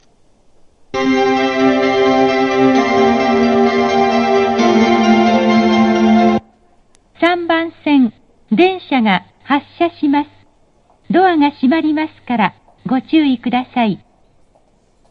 発車メロディー
接近放送 「Verde Rayo V2」です。
●スピーカー：National天井丸型
●音質：良